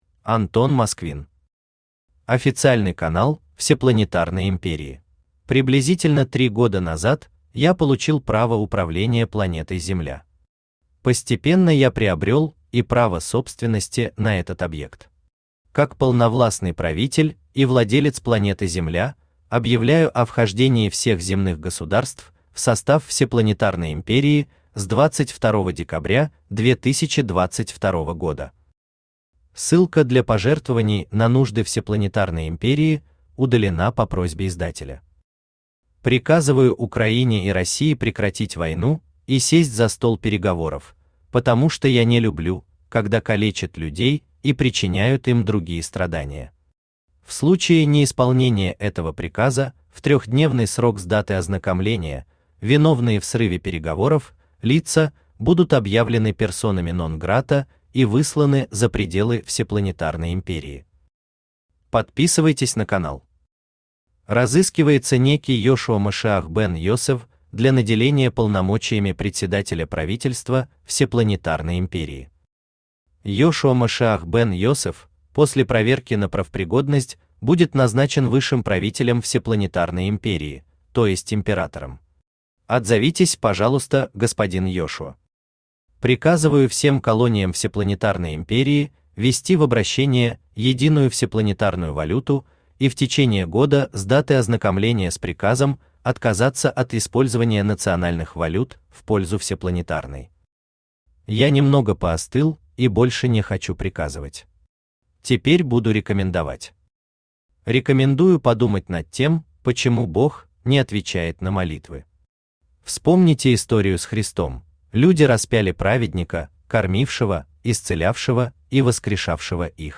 Aудиокнига Официальный канал Всепланетарной Империи Автор Антон Сергеевич Москвин Читает аудиокнигу Авточтец ЛитРес.